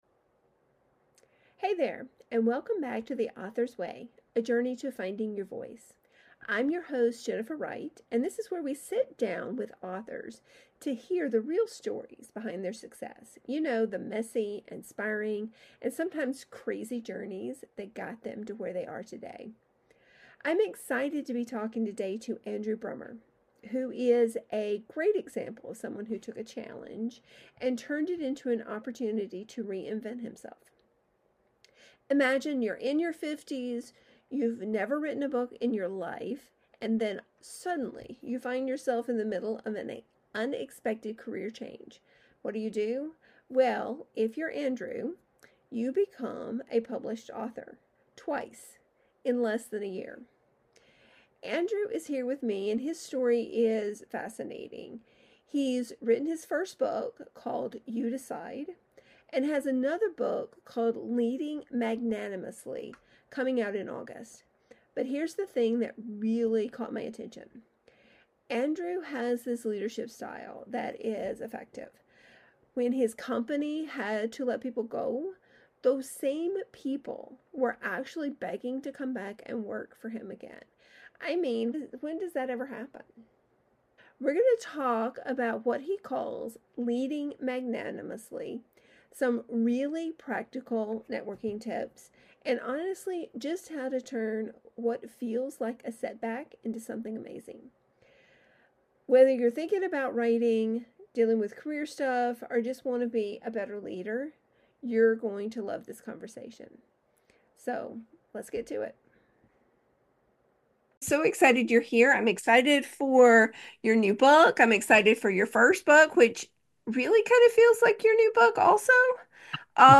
If you’ve ever thought about writing a book, wondered what it takes to get published, or simply love a good story—you’ll enjoy this conversation.